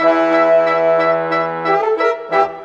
Rock-Pop 07 Brass _ Winds 04.wav